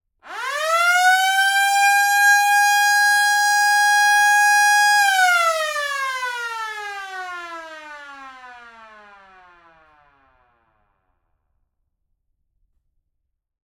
Sherman_M4A1_t11_foley_siren_long_CMC6.ogg